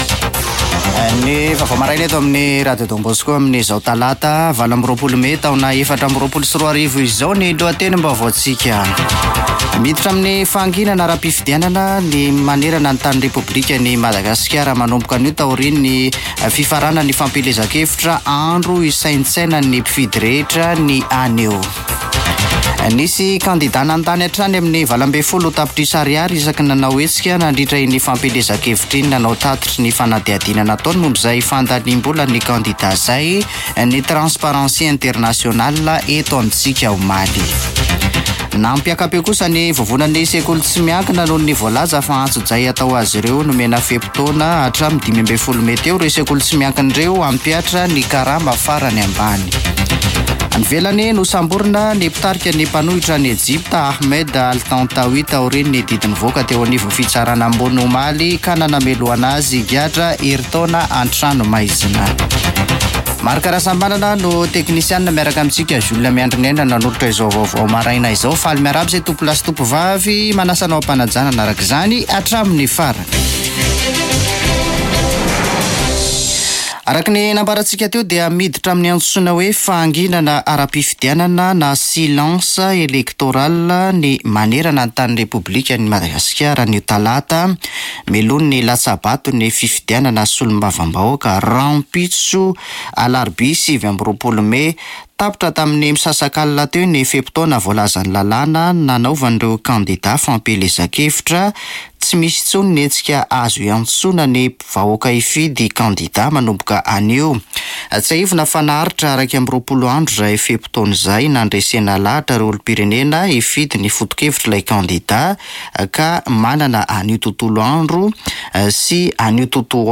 [Vaovao maraina] Talata 28 mey 2024